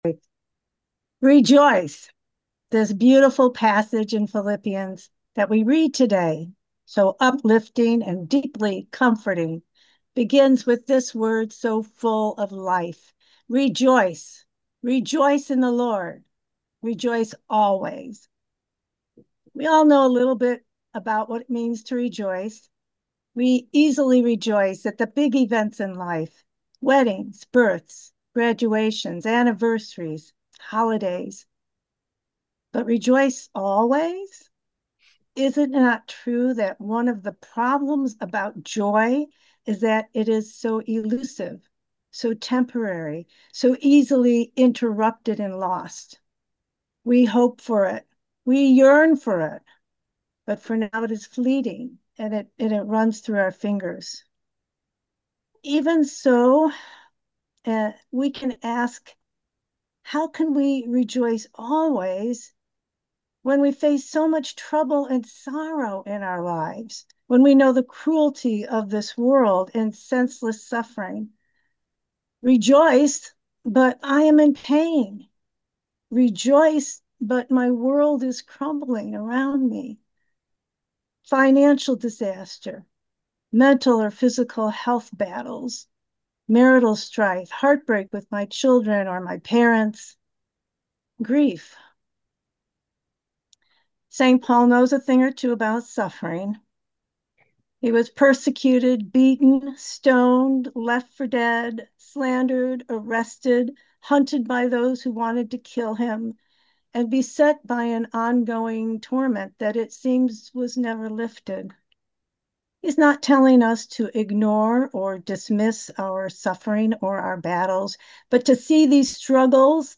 New Year’s Thanksgiving Prayer Service & Fellowship Zoom Event Reflections
MAIN REFLECTION